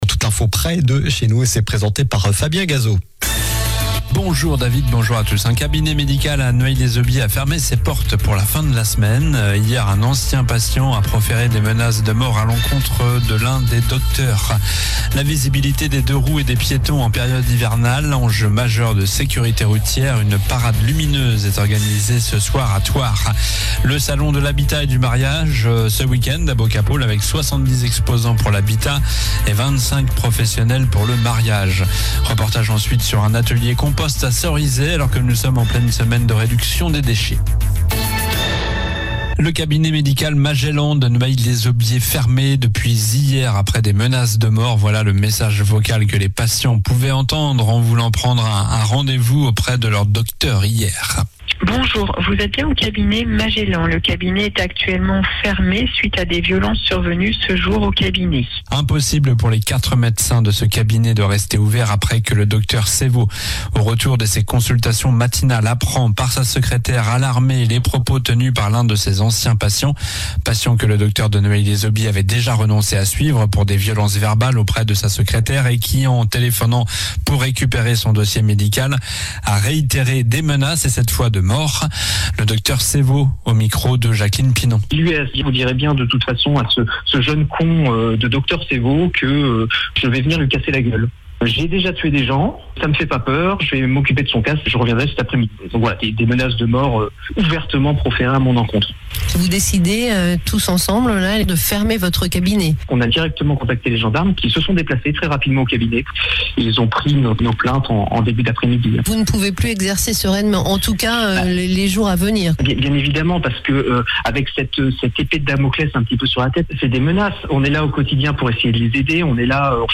Journal du vendredi 15 novembre (midi)
- Reportage ensuite sur un atelier compost à Cerizay... 0:00 11 min 46 sec